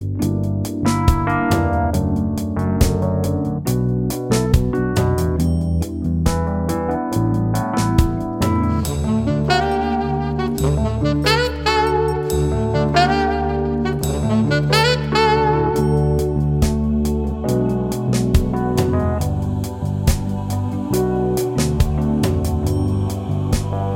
No Guitars Pop (1980s) 4:13 Buy £1.50